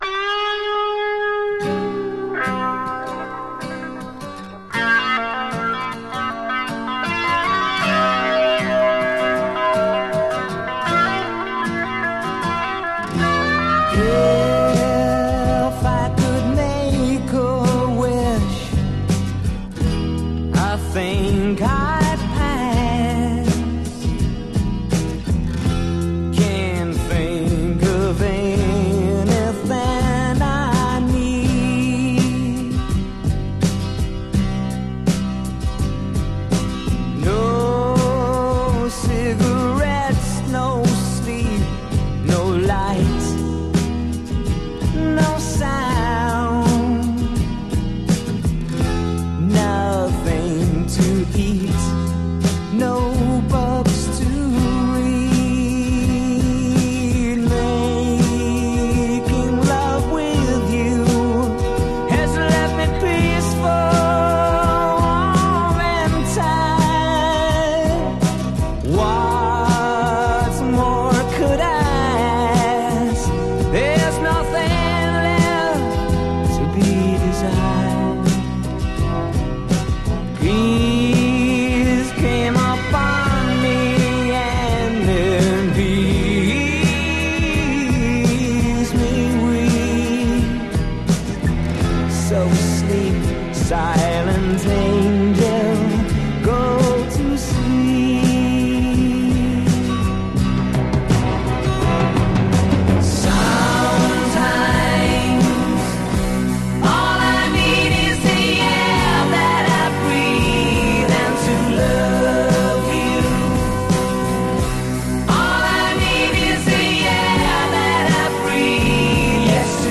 Genre: Power Pop
ballad
awe-inspiring harmonies